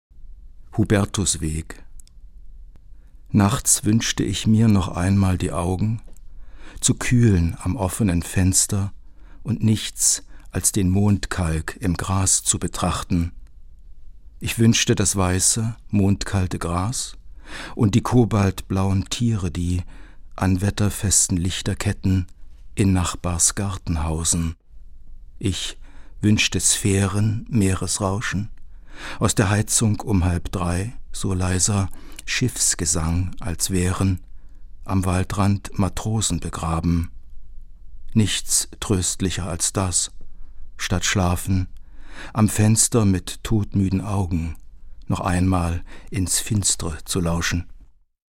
Gelesen von Lutz Seiler.